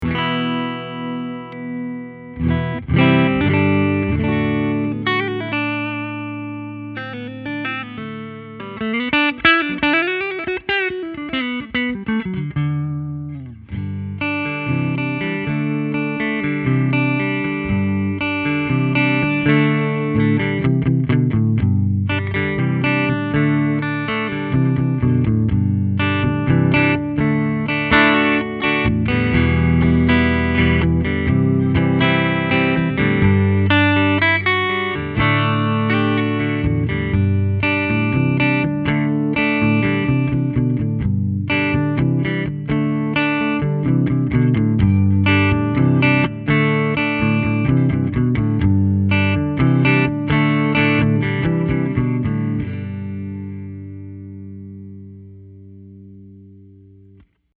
Here are 12 quick, 1-take MP3 sound files of myself playing this guitar, to give you an idea of what to expect. The guitar has great tone, sustain, and body, and is also capable of some nice controlled feedback effects. These tracks are all recorded using a Peavey Studio Pro 112 amp with a a Sennheiser MD441 mic, recorded straight into a Sony PCM D1 flash recorder, and MP3s were made in Logic, with no EQ or effects.
(Original in G)
It also has this Beatles-era psychadelic vintige vibe to it using the clean sounds, and it has a very nice jangly sound to the basses, and the trebles ring out nicely to give the guitar a wonderful clarity and deffinition.